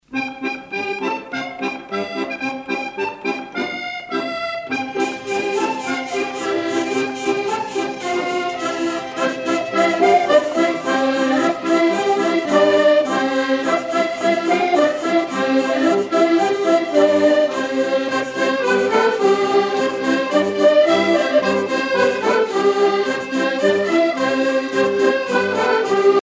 danse : rondeau
Pièce musicale éditée